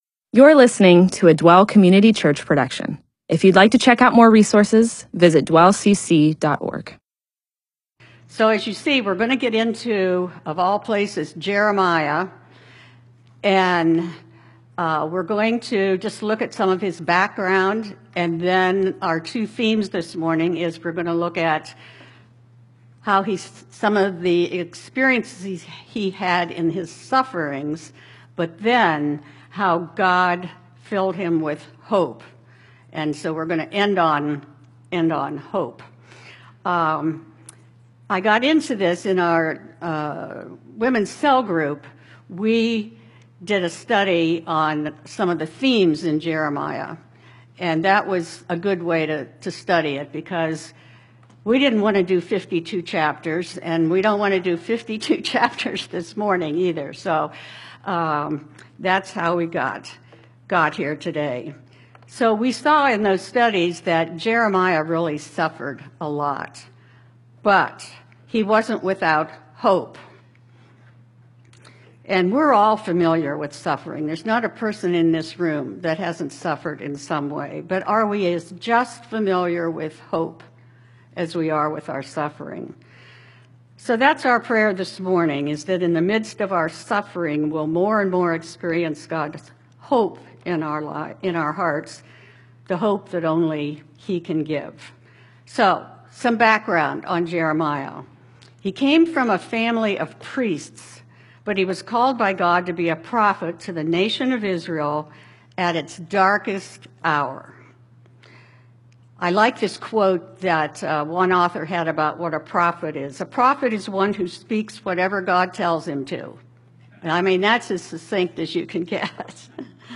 Bible teaching (presentation, sermon) on Jeremiah 23